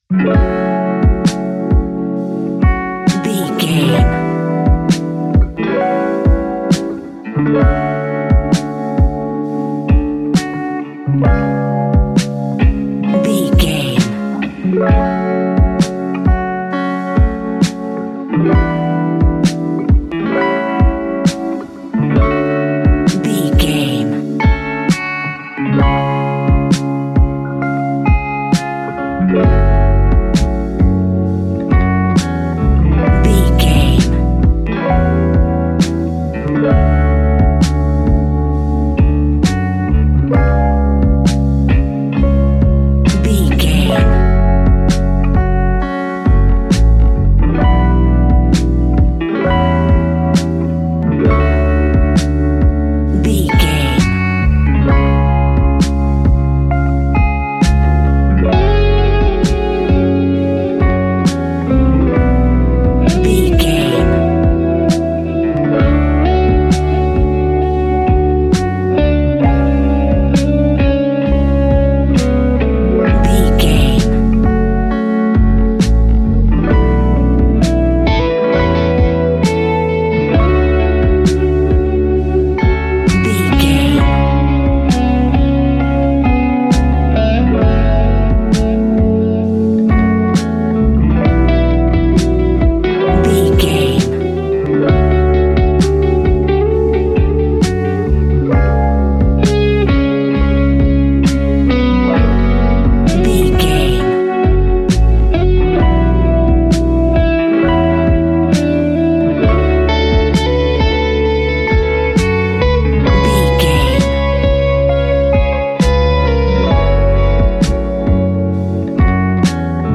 Ionian/Major
F♯
laid back
Lounge
sparse
chilled electronica
ambient